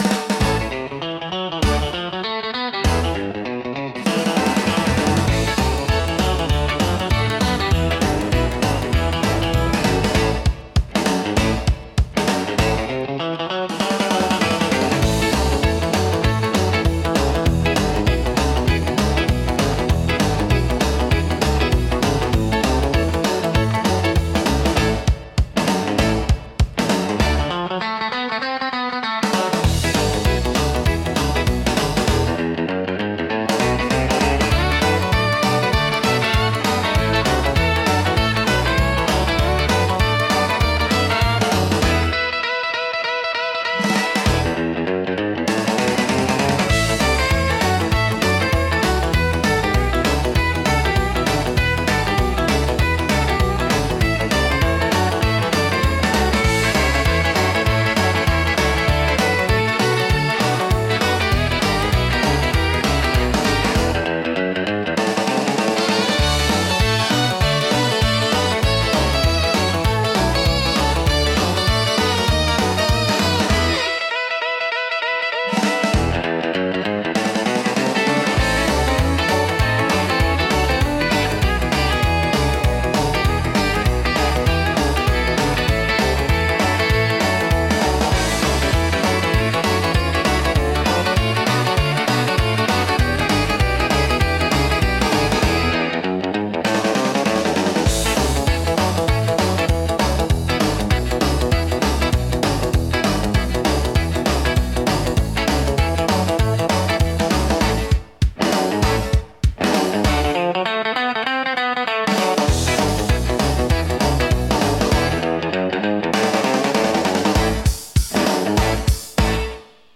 ベースのスラップ奏法やキャッチーなメロディが躍動感と楽しさを強調します。
懐かしさと若々しいエネルギーが共存し、楽しい空気づくりに効果的なジャンルです。